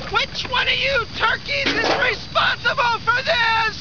screams what I had been feeling throughout the whole film.